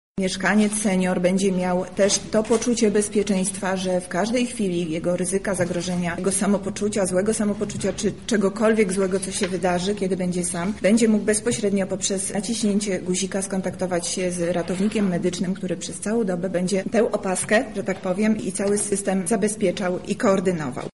O jej podstawowych funkcjach mówi Zastępca Prezydenta Miasta ds. Społecznych Monika Lipińska: